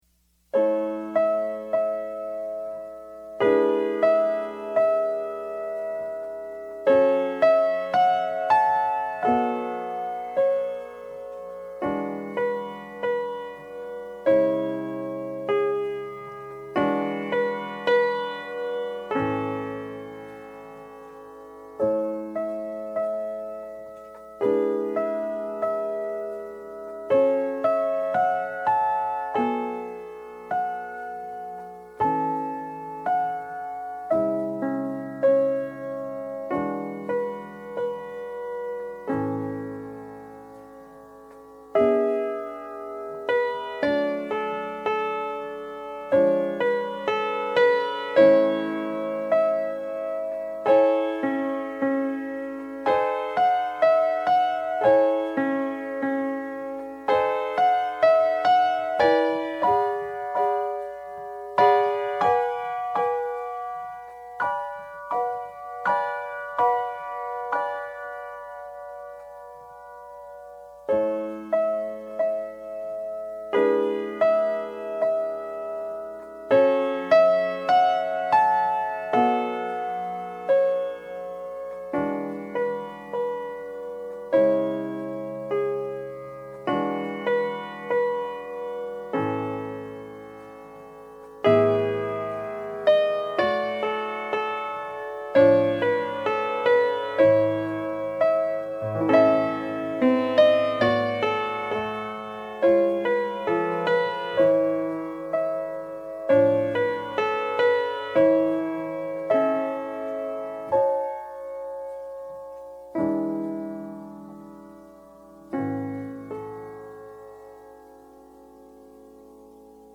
PIANO SOLO Sacred Music, Piano Solo, Offertory, & Prelude
DIGITAL SHEET MUSIC - PIANO SOLO